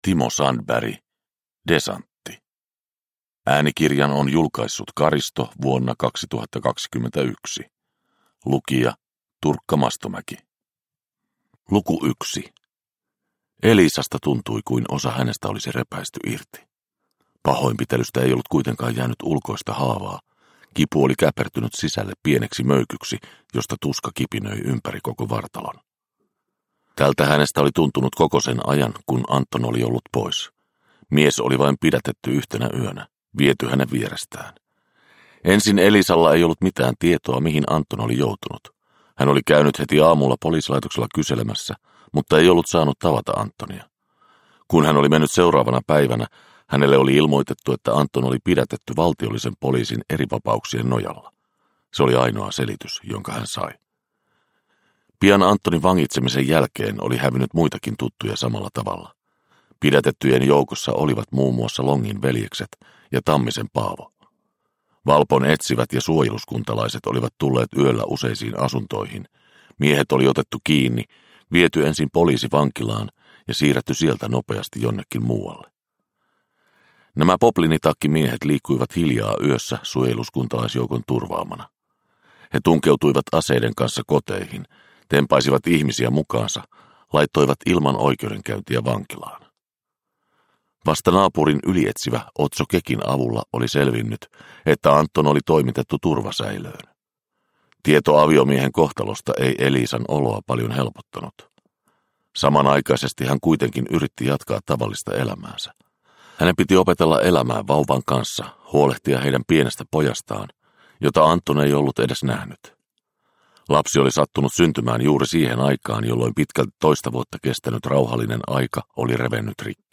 Desantti – Ljudbok – Laddas ner